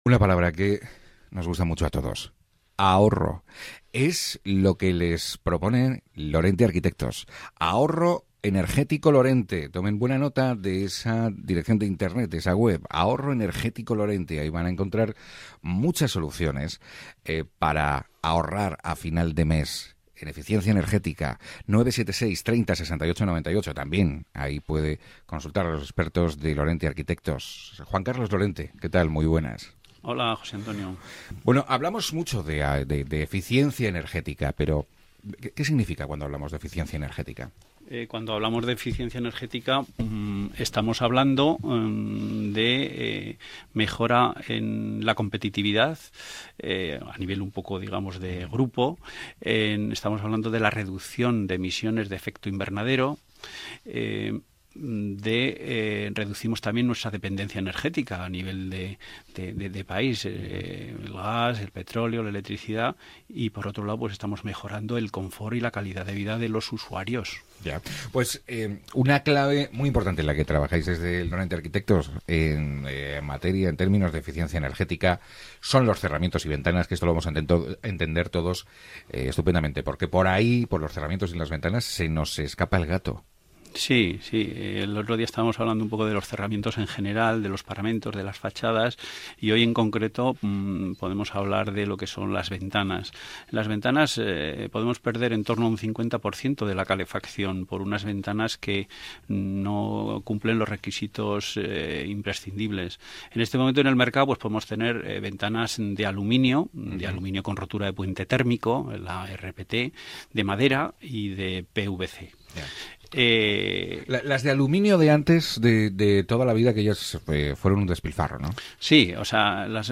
4ª Entrevista sobre eficencia energética